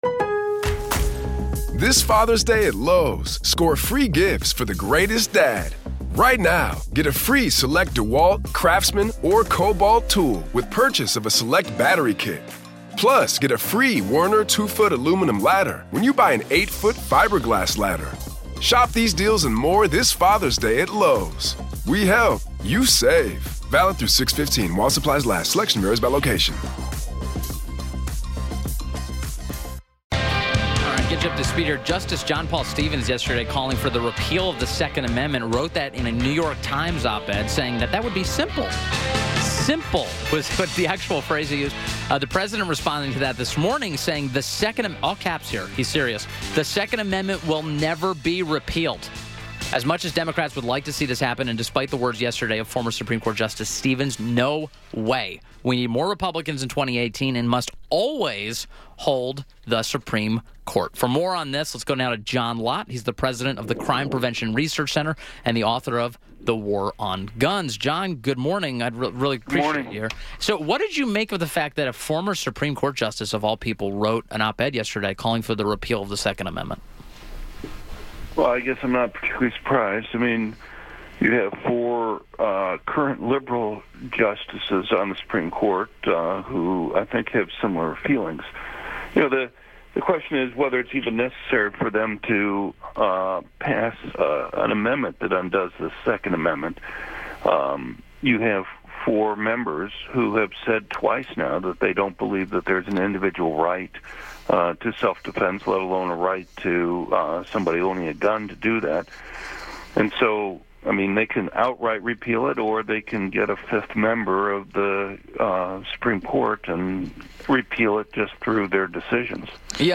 WMAL Interview - JOHN LOTT - 03.28.18